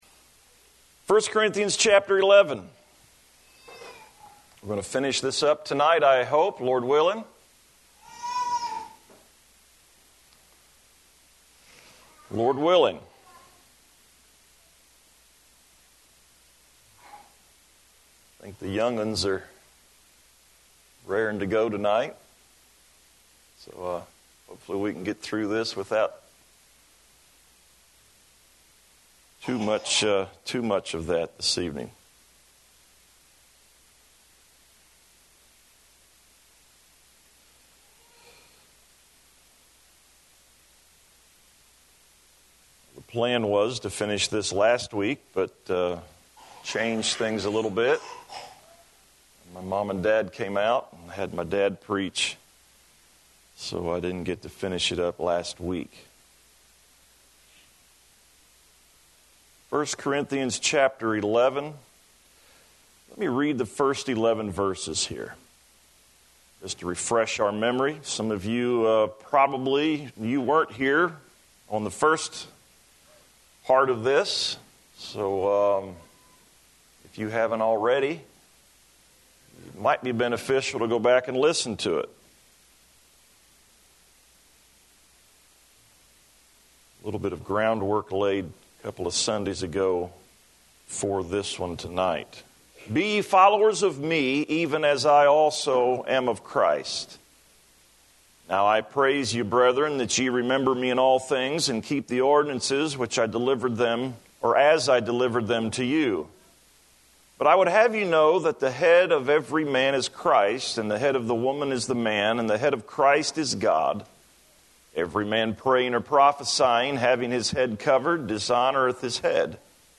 Save Audio This sermon